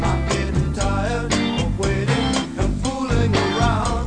1 channel
sghprogramerror.mp3